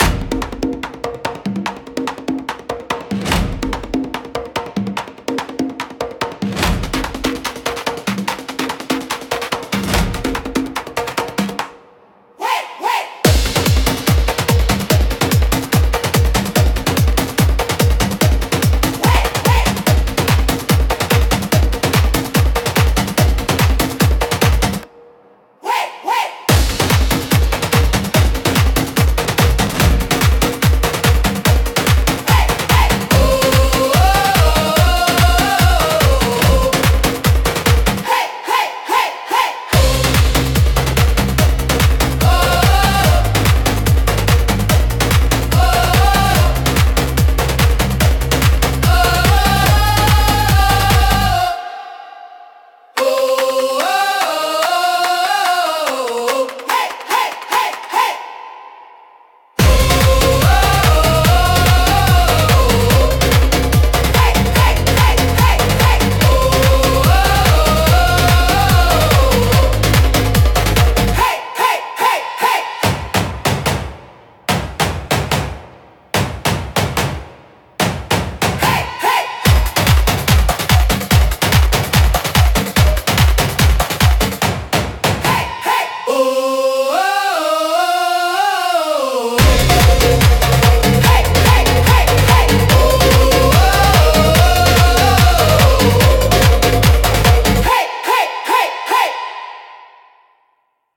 迫力と神秘性が共存するジャンルです。